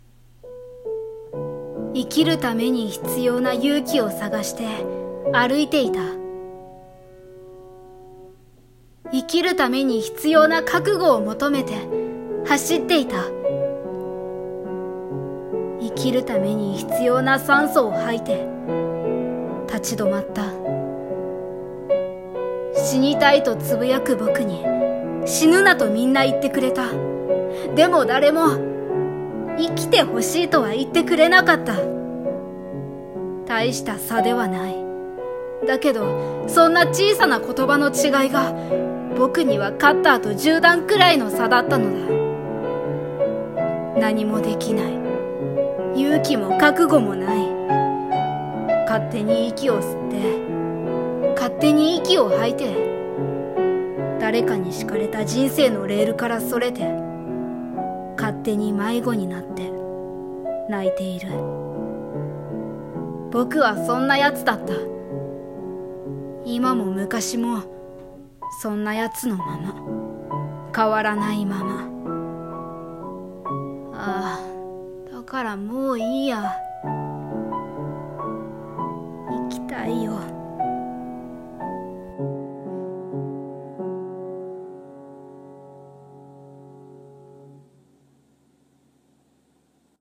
【声劇台本】生きたい